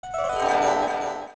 off is an edited version of a stock sound by Warner Bros. Sound Effects Library, consisting of music bells ringing.